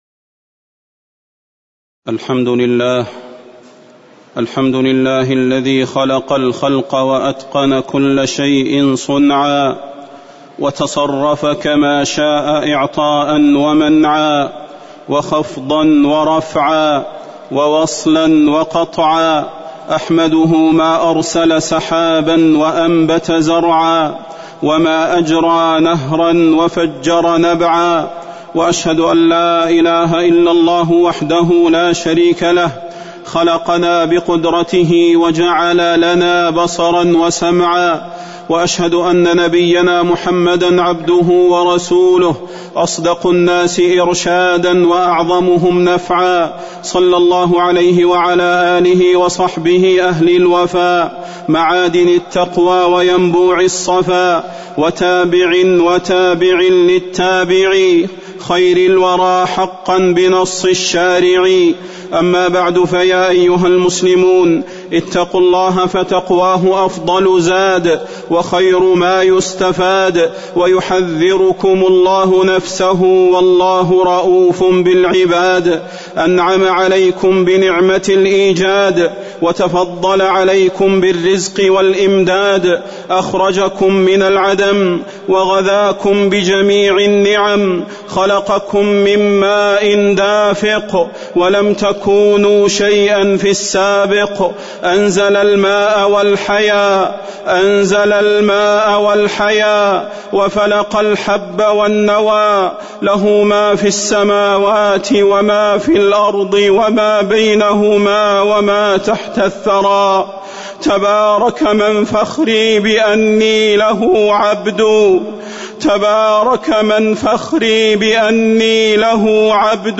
خطبة الاستسقاء - المدينة- الشيخ صلاح البدير
تاريخ النشر ٧ جمادى الأولى ١٤٤١ هـ المكان: المسجد النبوي الشيخ: فضيلة الشيخ د. صلاح بن محمد البدير فضيلة الشيخ د. صلاح بن محمد البدير خطبة الاستسقاء - المدينة- الشيخ صلاح البدير